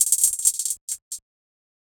Hihat Roll 5.wav